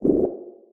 Sfx_creature_penguin_waddle_voice_04.ogg